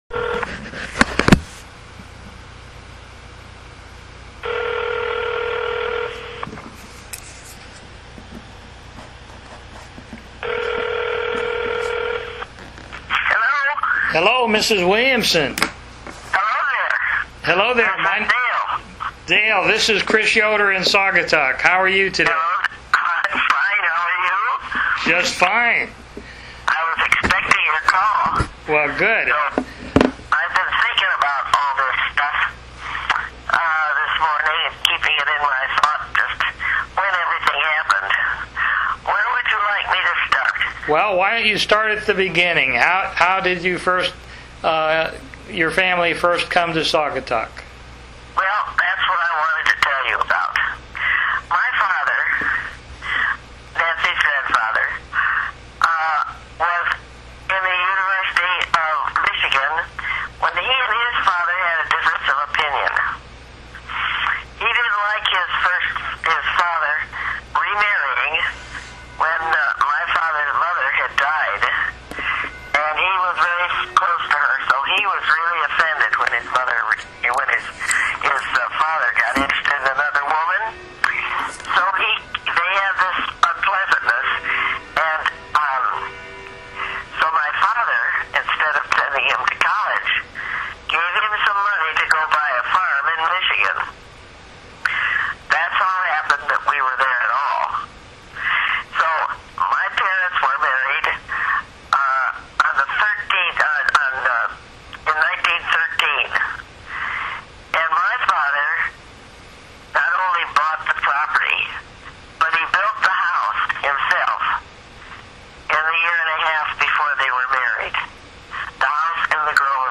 by telephone.